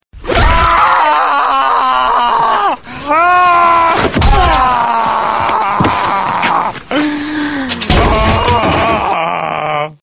Прикольные звонки